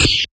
描述：打算用于游戏创作：更大和更小的宇宙飞船的声音和其他在没有空气的太空中非常常见的声音
标签： 宇宙飞船 游戏创作 的WarpDrive 空间 外形 移相器
声道立体声